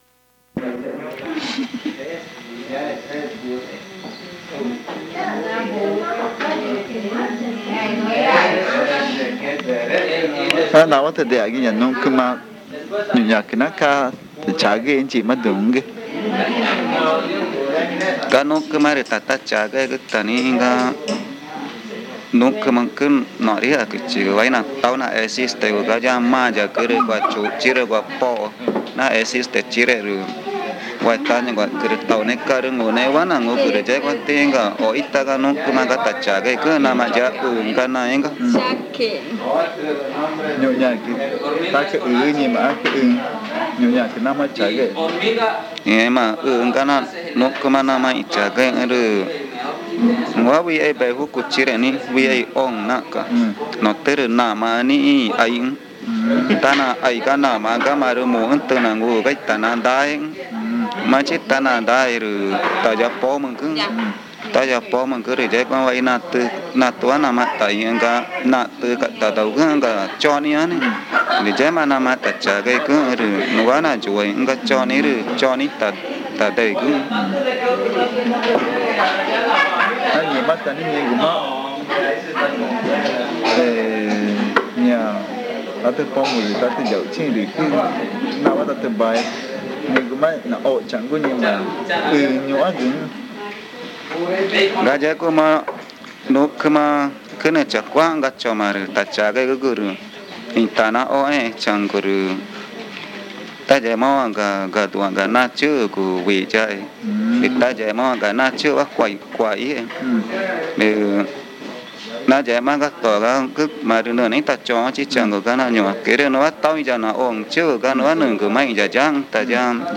Encuesta léxica y gramatical 20. Santa Lucía
El audio contiene los lados A y B del casete.